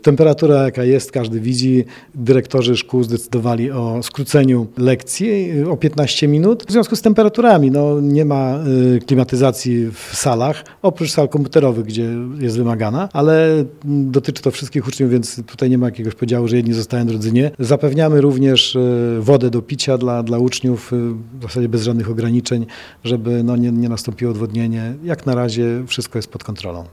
Mówi starosta ełcki, Marek Chojnowski.